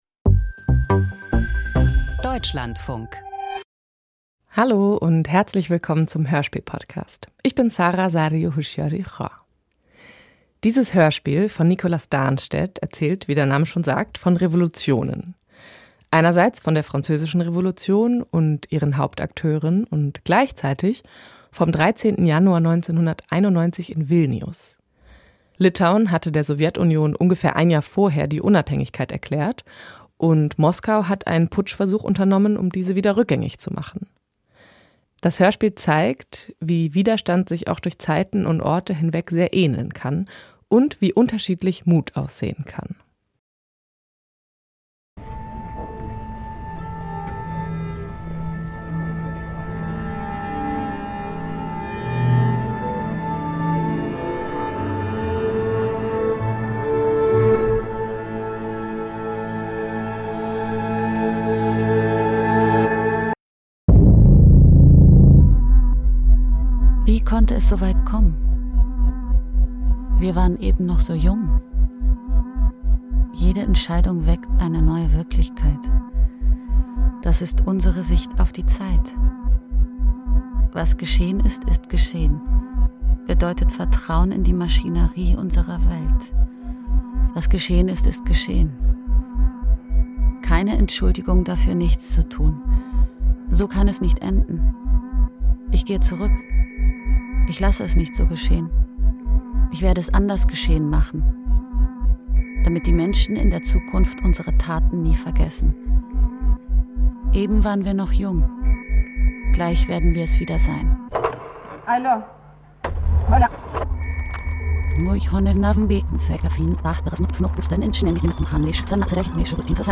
Ein akustischer Trip durch die Französische Revolution und Momente des Wandels - Revolutionsstück
Inwiefern kann ich als Einzelner etwas verändern? Eine Collage der Revolutionen in Frankreich und im Baltikum macht Antworten sichtbar.